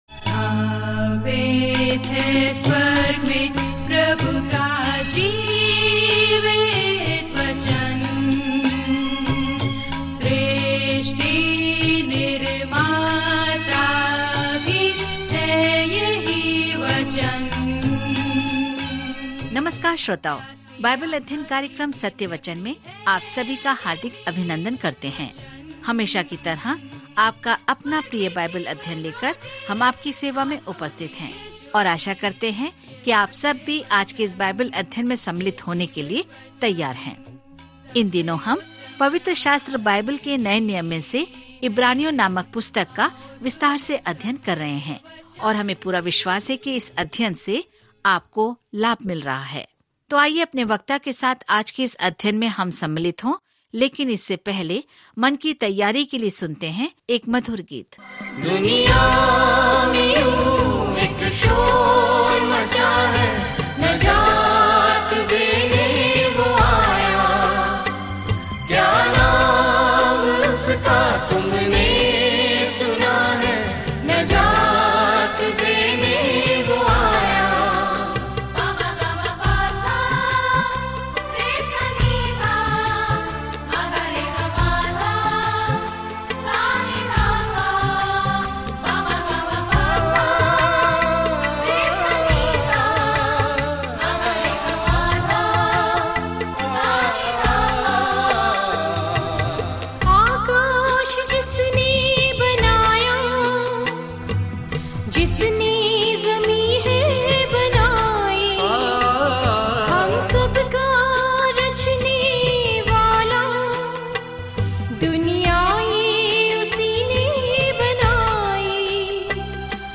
जब आप ऑडियो अध्ययन सुनते हैं और भगवान के वचन से चुनिंदा छंद पढ़ते हैं तो इब्रानियों के माध्यम से दैनिक यात्रा करें।